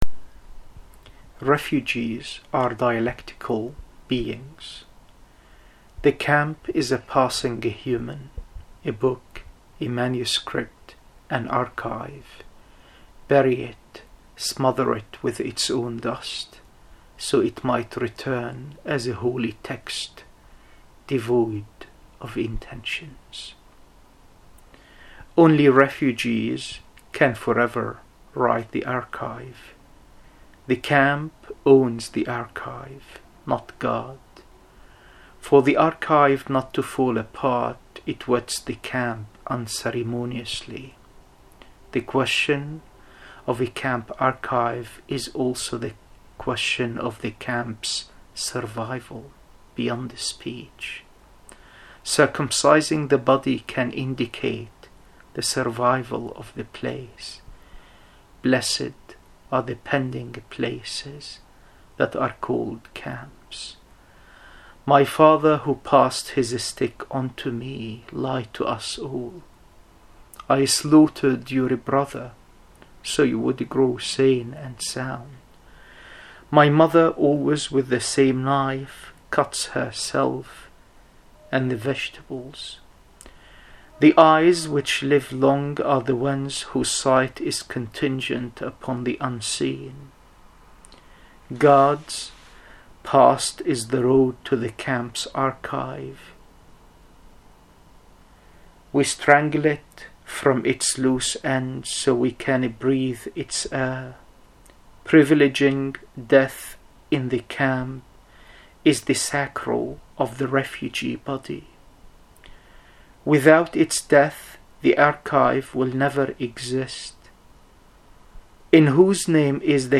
reading his poem